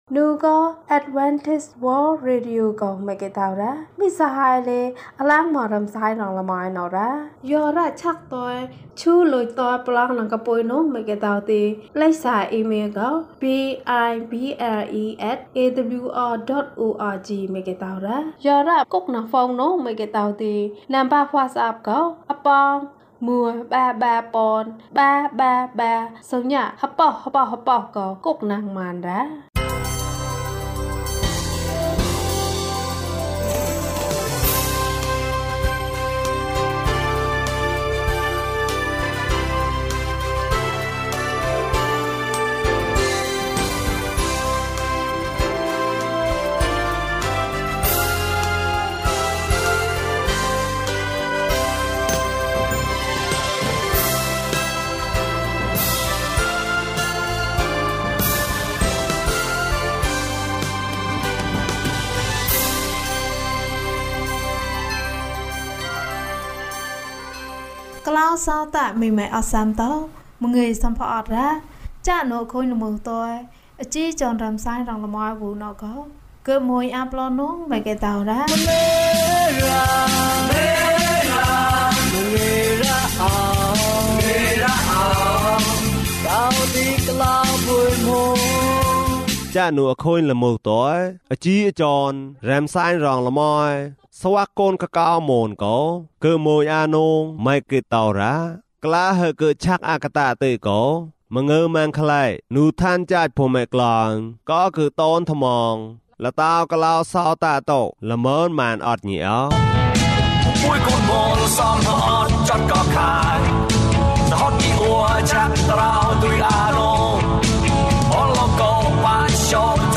ကျန်းမာရေးနှင့် ကလေးပုံပြင်။ ဓမ္မသီချင်း။ တရားဒေသနာ။